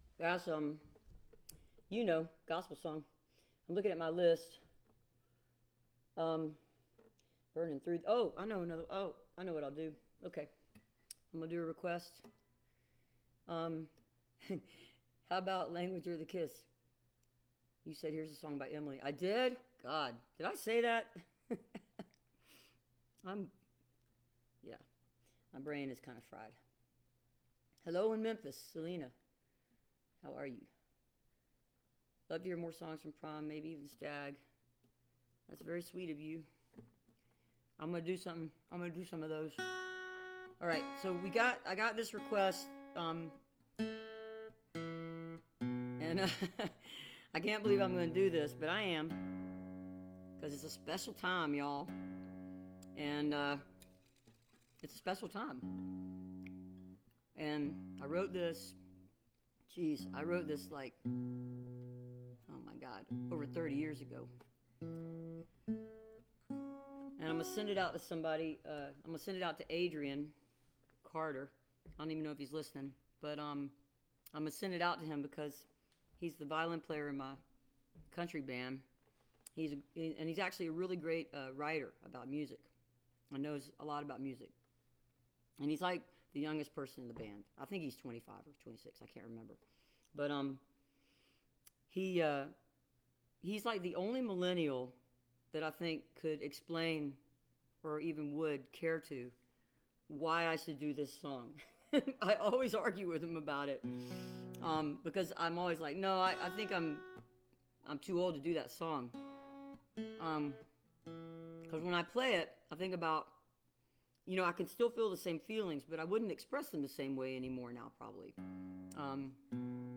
(captured from the facebook live video stream)
12. talking with the crowd (2:19)